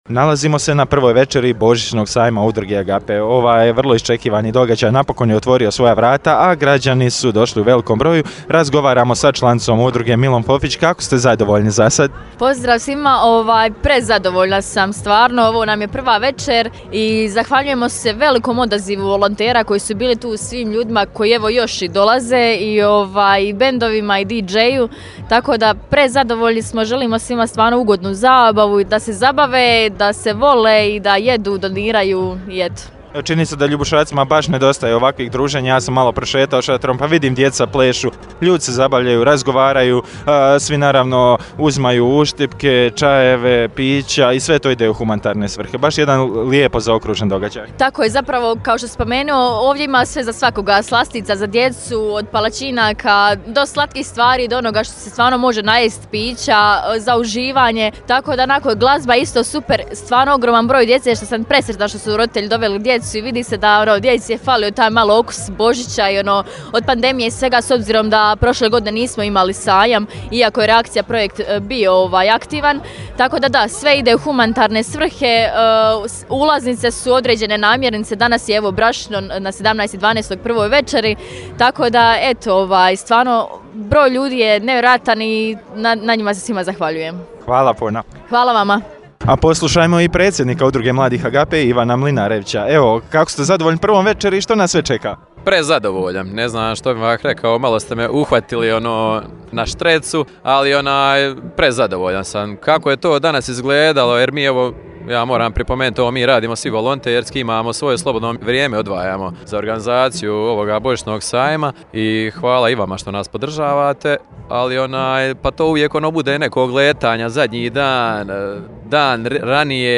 Sinoć je svoja vrata otvorio Božićni sajam Udruge mladih Agape, a ako se doista „po jutru dan poznaje“ prva večer sajma bila je dovoljan razlog za radost, okupio se velik broj Ljubušaka koji su se družili pod šatorom na glavnom gradskom trgu, uz sjajnu svirku uživo.
Posjetitelje je u jednom kutu šatora čekao i foto kutak, a prvu večer sajma pohodili su i mladi i stari, djeca su plesala i skakala pred pozornicom, a stariji su se družili za stolovima.